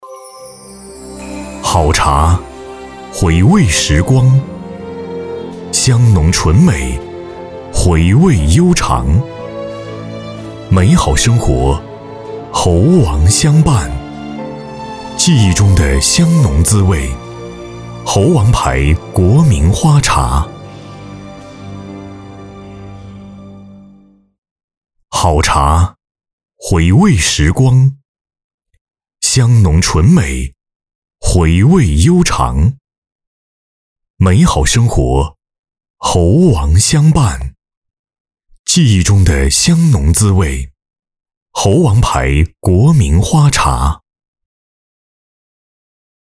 A男172号
【广告】大气感情 央视风格
【广告】大气感情 央视风格.mp3